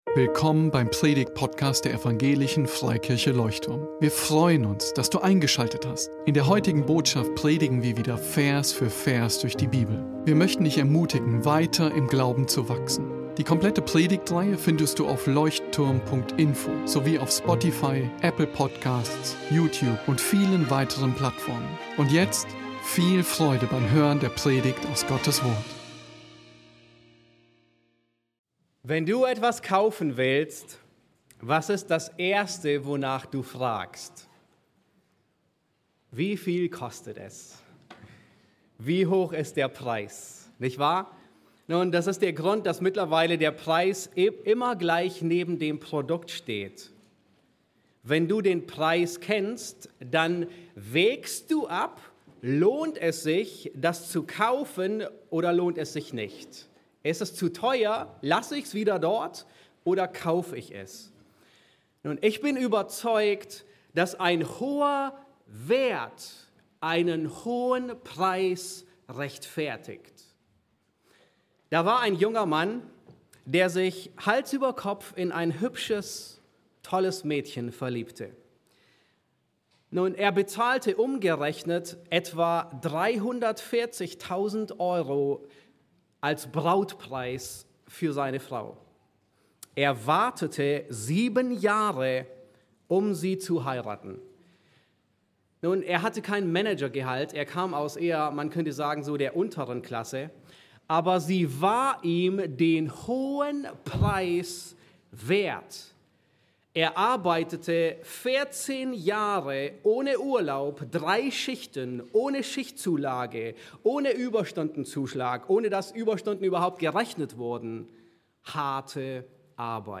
Besuche unseren Gottesdienst in Berlin.